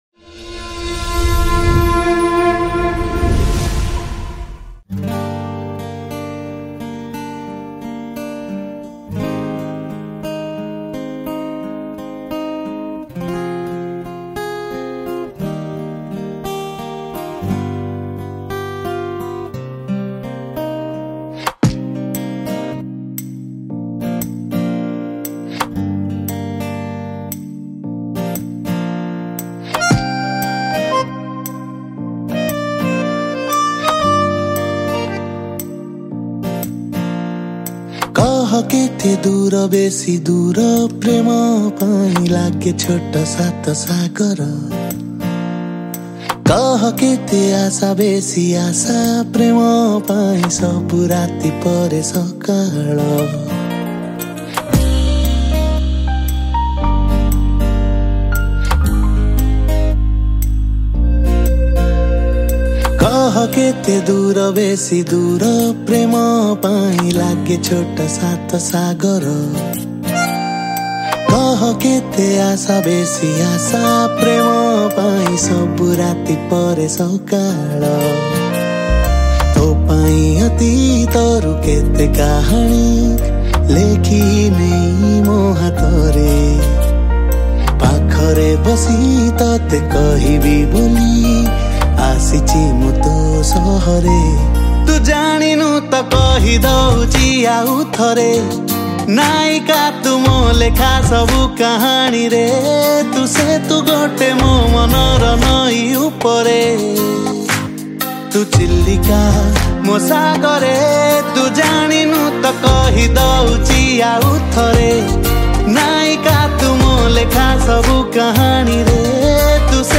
Guitar
Keyboard